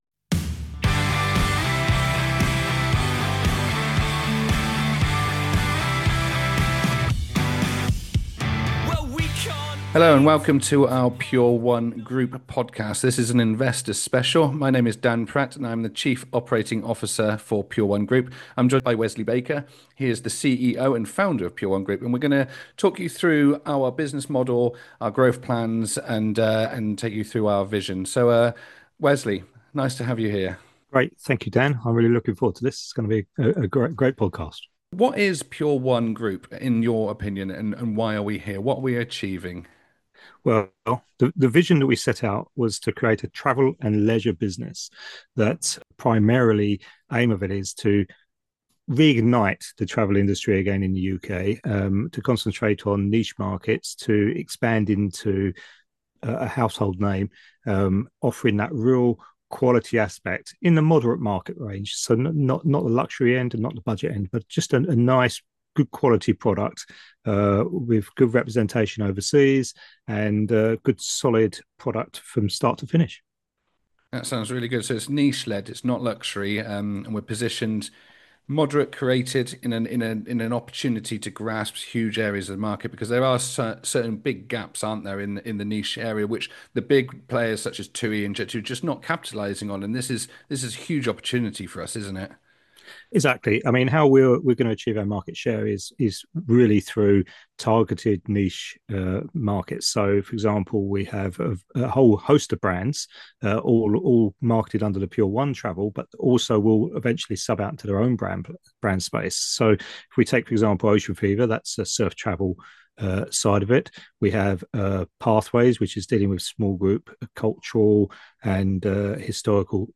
They outline the growth potential of PURE ONE, the strategy behind the raise, and the long-term vision of building a premium travel group with global reach. This candid conversation gives shareholders and investors direct insight into where PURE ONE is headed and why now is the right time to be part of the journey.